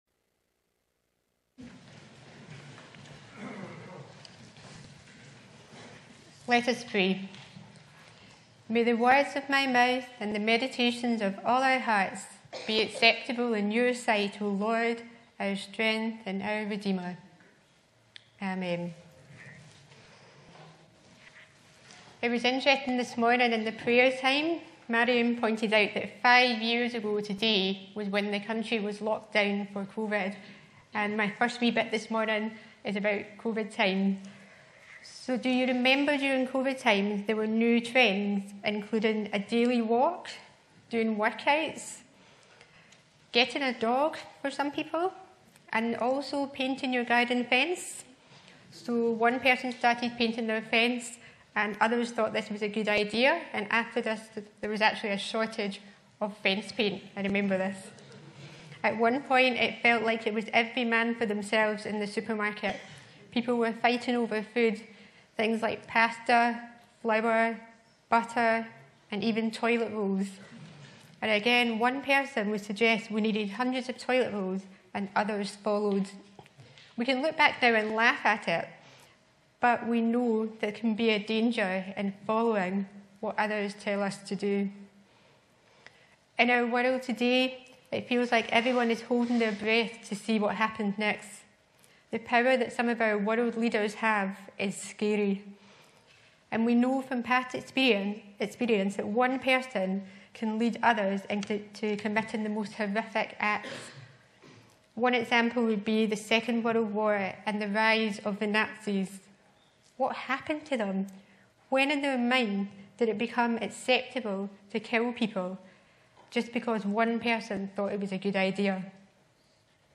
Sermons in this Series
Bible references: Luke 23:26-43 Location: Upper Braes Parish Church – Brightons Ministry Centre Show sermon text Sermon keypoints: 'Father forgive them, for they do not know what they are doing.'